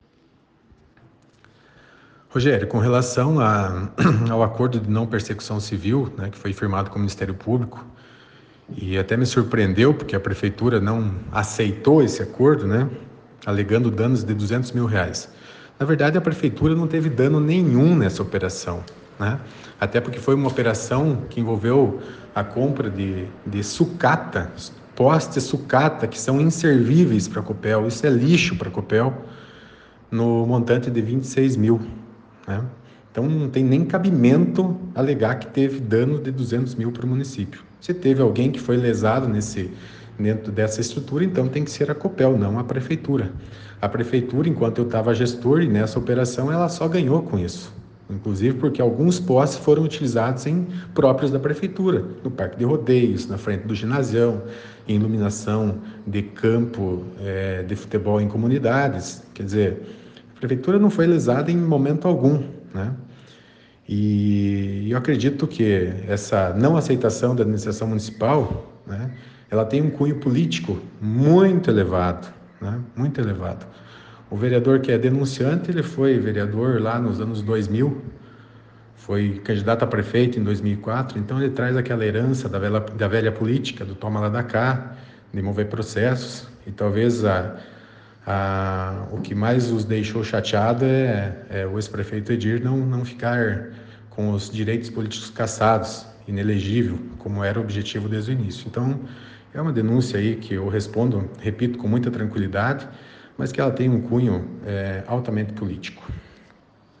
Ex-prefeito fala sobre a denúncia
E Edir Havrechaki falou sobre o assunto na edição desta quinta-feira, dia 13, do ‘Jornal da Cruzeiro’ (ouça nos áudios abaixo).